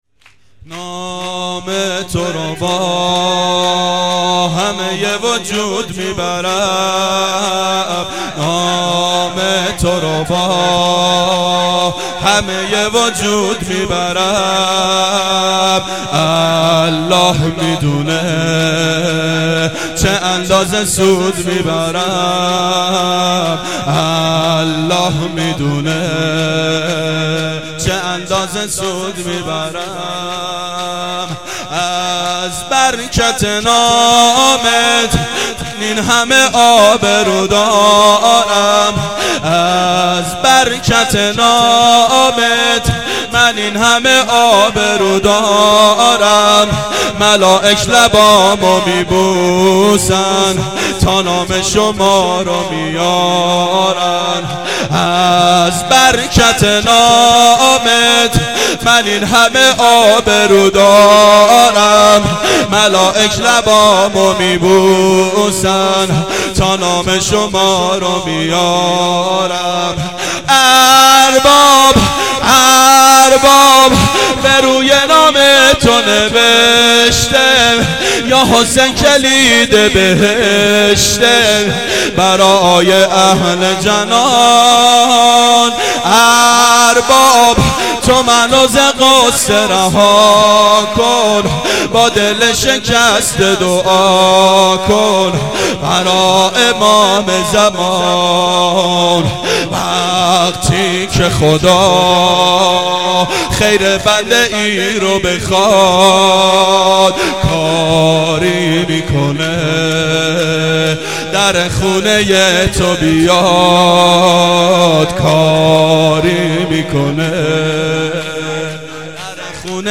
شب سوم محرم 89 گلزار شهدای شهر اژیه